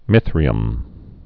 (mĭthrē-əm)